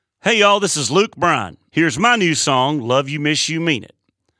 LINER Luke Bryan (LYMYMI) 2